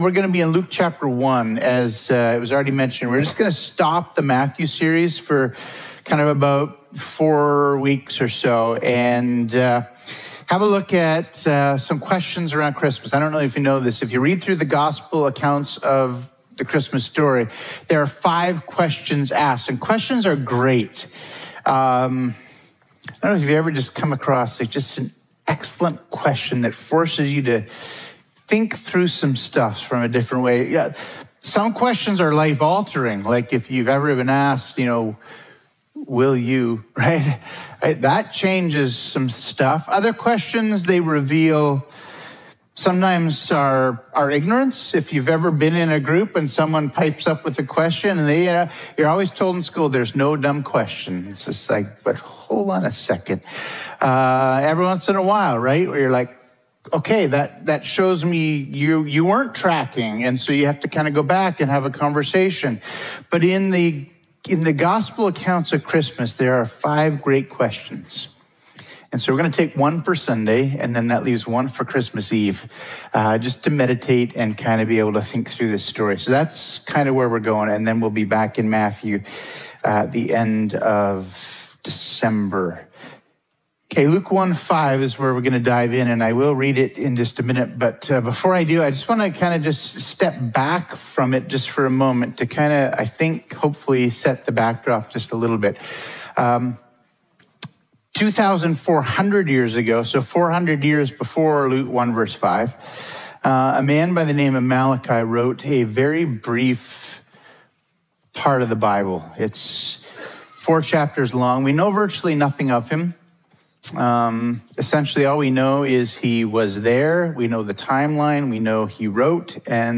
Sermons | Emmanuel Baptist Church